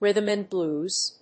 アクセントrhýthm and blúes